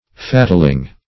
fatling - definition of fatling - synonyms, pronunciation, spelling from Free Dictionary
Fatling \Fat"ling\, n. [Fat + -ling.]